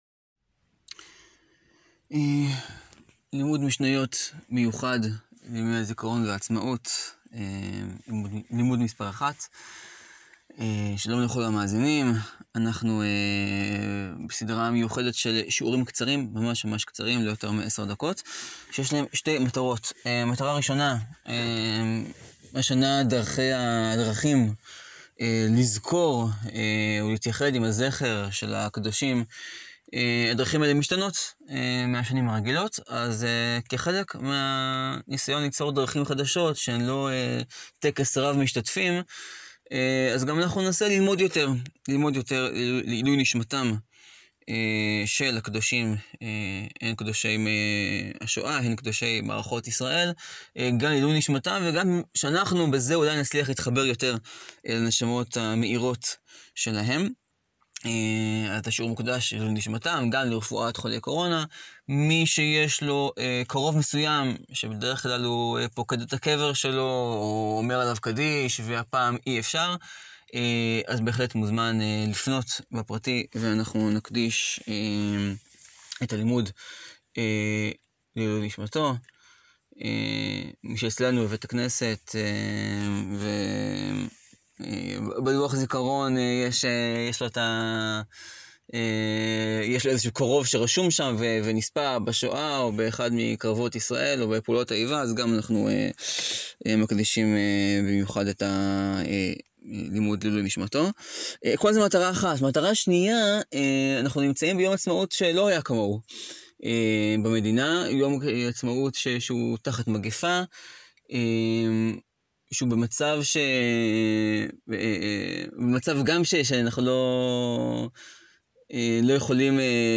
לימוד יומי בשאלה הנשאלת לראשונה מזה 72 שנה: האם יש משמעות לחגיגת עצמאות לבד בבית?